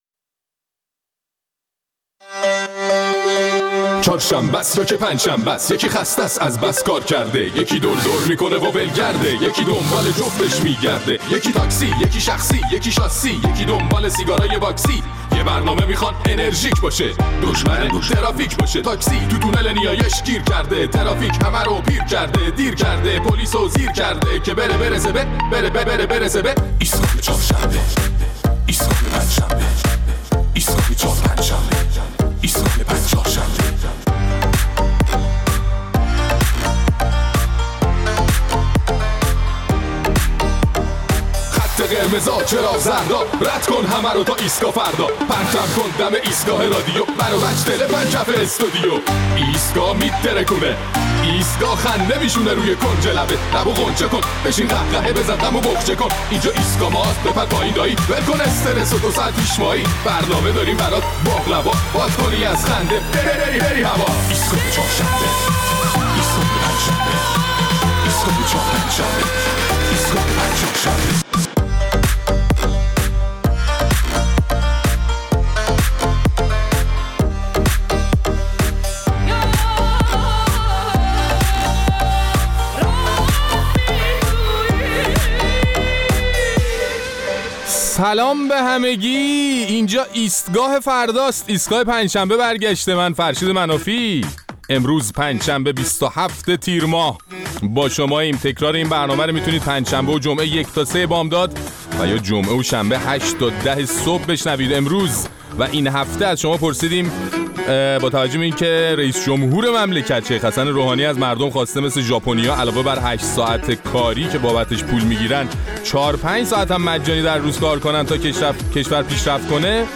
در این برنامه ادامه نظرات مخاطبین ایستگاه فردا را درباره صحبت‌های اخیر حسن روحانی که مردم ایران را به کار کردن مثل ژاپنی‌ها دعوت کرده بود، می‌شنویم.